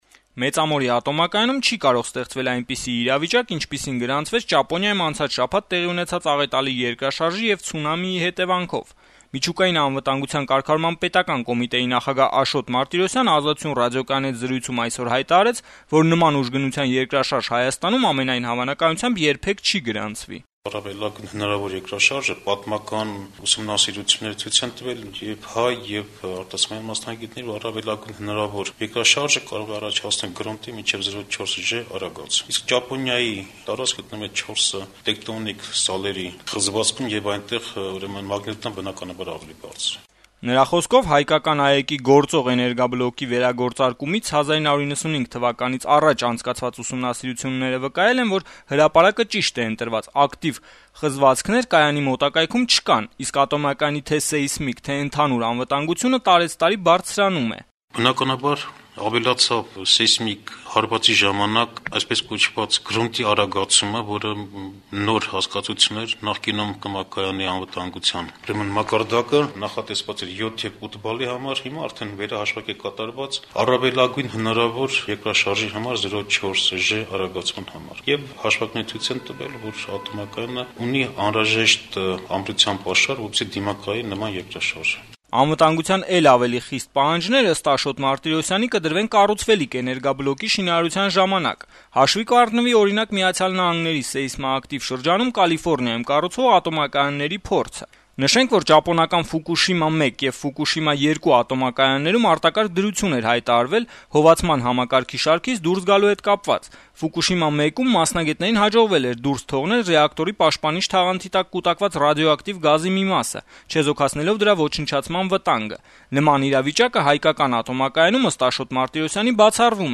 Միջուկային անվտանգության կարգավորման պետական կոմիտեի նախագահ Աշոտ Մարտիրոսյանը «Ազատություն» ռադիոկայանի հետ զրույցում երկուշաբթի օրը, վկայակոչելով ուսումնասիրությունների փորձը, ասաց, որ Ճապոնիայում անցած շաբաթ տեղի ունեցած երկրաշարժի ուժգնությամբ երկրաշարժ Հայաստանում, ամենայն հավանականությամբ, երբեք չի գրանցվի: